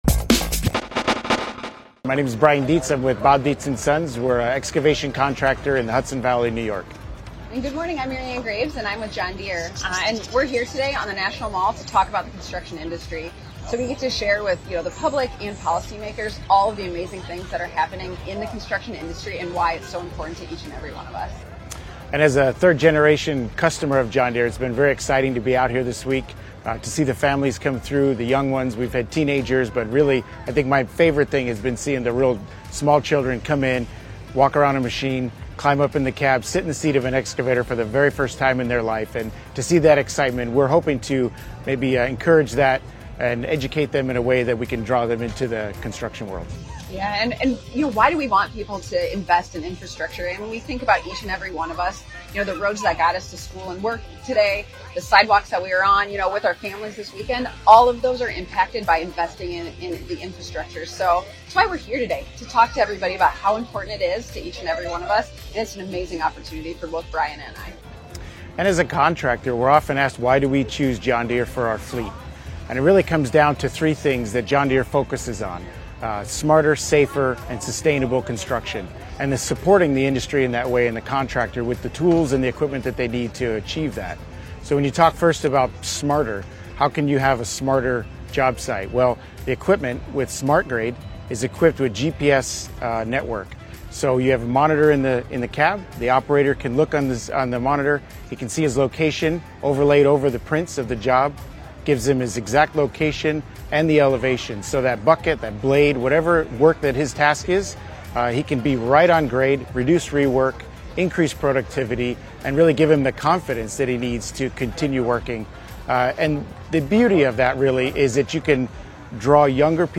The proper funding and maintenance of our aging infrastructure are critical, and more sustainable construction solutions are essential. LifeMinute was on the National Mall with manufacturers and associations to discuss the importance of supporting infrastructure and the need for investment in communities all over the country.